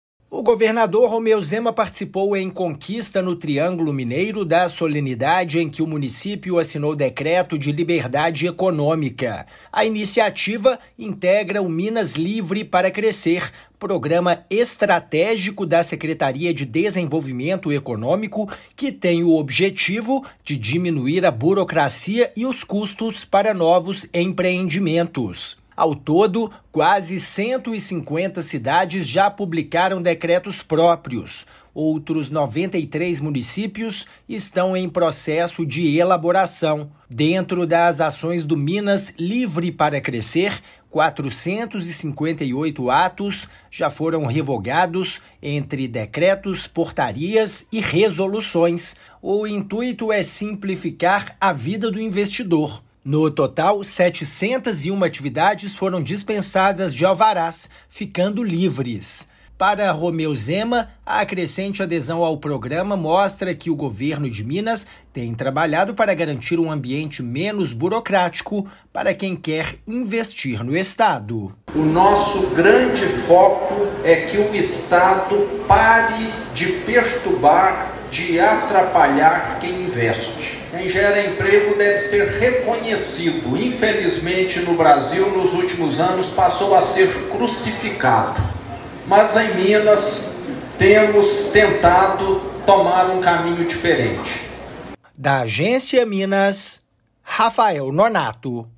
Durante visita do governador, prefeitura de Conquista, no Triângulo Mineiro, assinou decreto de liberdade econômica. Ouça matéria de rádio.